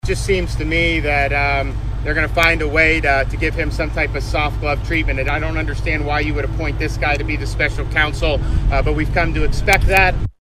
(Harlan, IA) — Florida Governor Ron DeSantis is speaking on the criminal tax investigation of Hunter Biden.
While campaigning in Harlan, Iowa, DeSantis said the president’s son would be in jail by now if he were a Republican. His comment was in response to the news a special counsel was appointed in Hunter’s case.